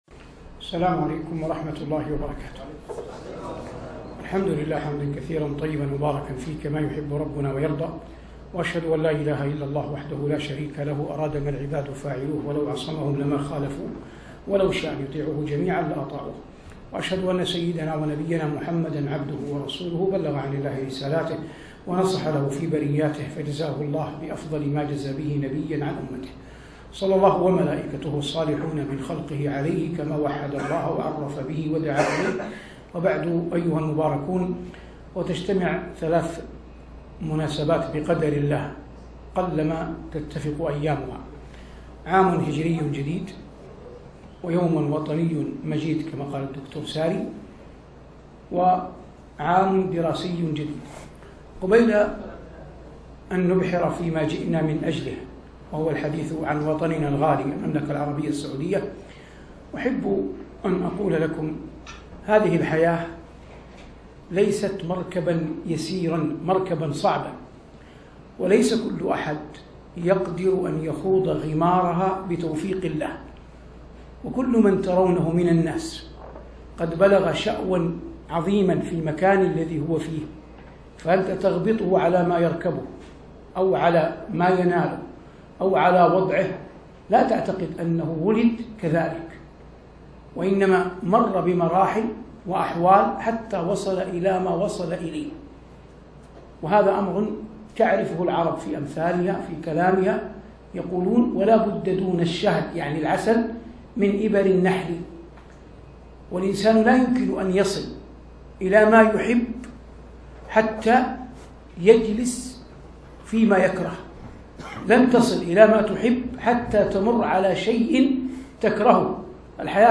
كلمة بعنوان "حب الوطن من الإيمان" بكلية العلوم الطبية في المدينة المنورة